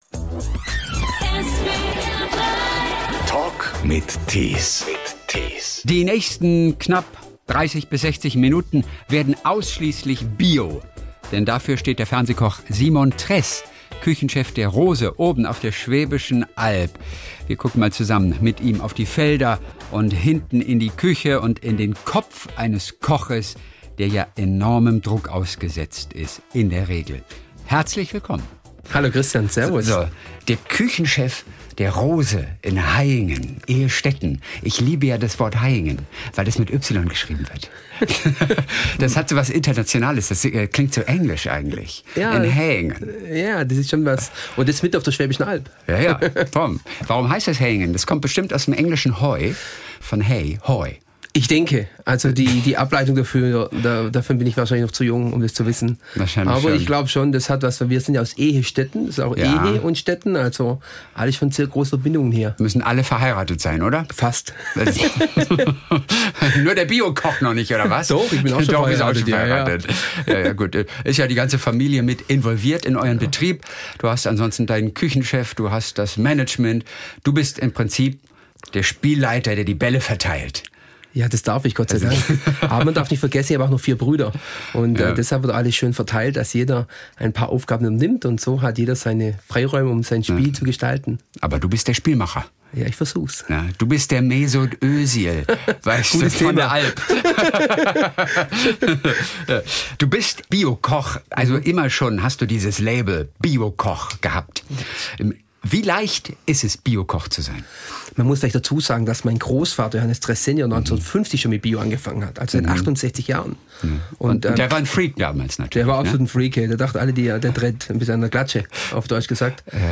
Der Talk in SWR3